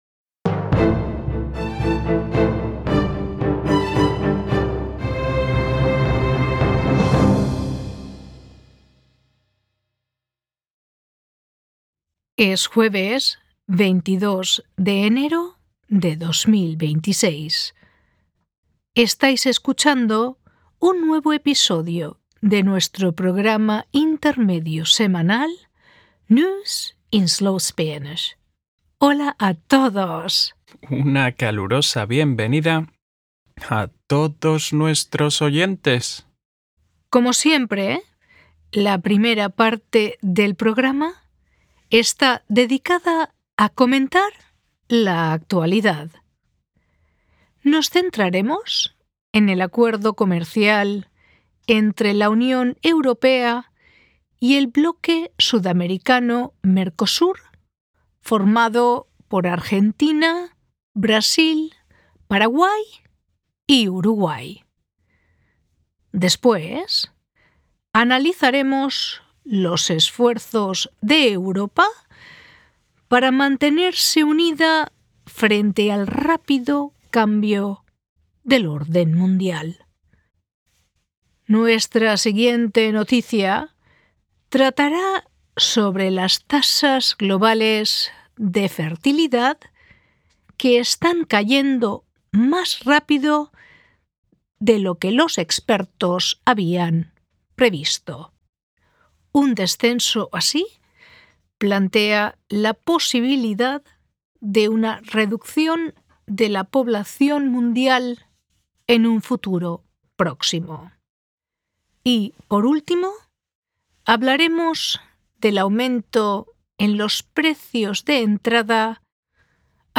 News in Slow Spanish (full)